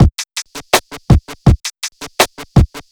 HP082BEAT4-R.wav